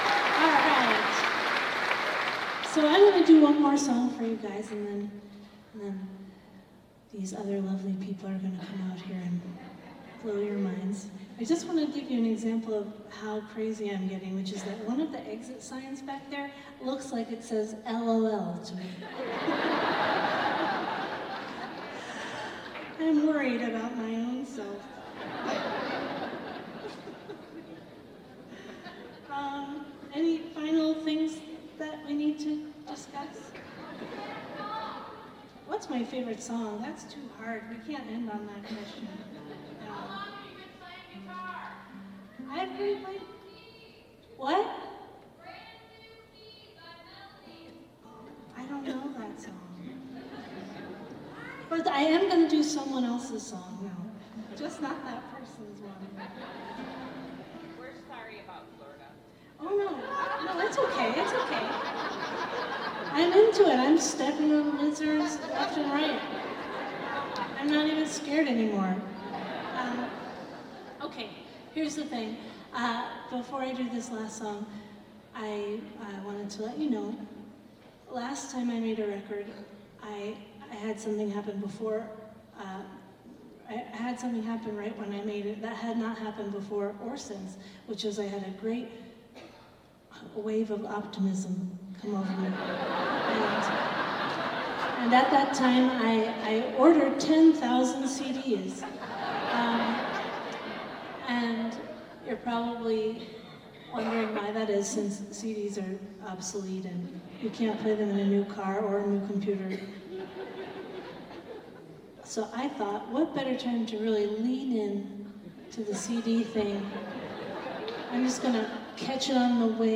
lifeblood: bootlegs: 2024: 2024-05-07: barbara b. mann performing arts hall - fort myers, florida